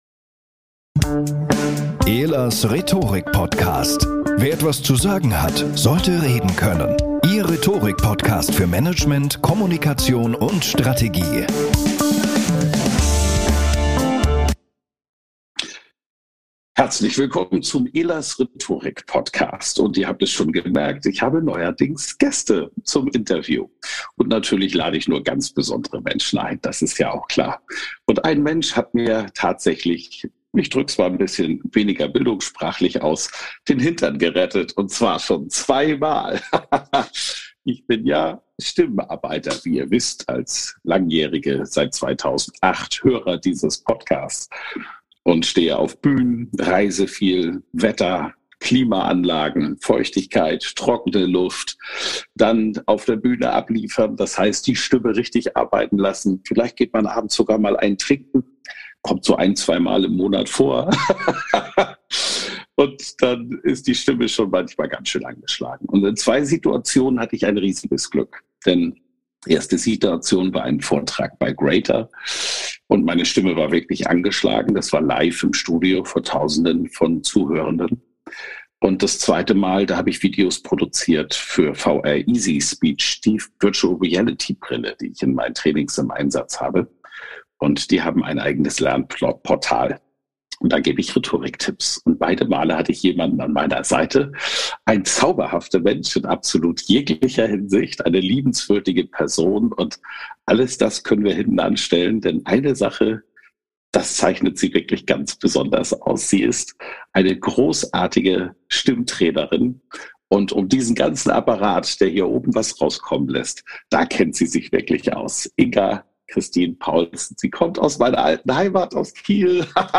Regelmäßige LIVE Talks mit den besten Expert*innen aus der DACH-Region zum Thema Rhetorik!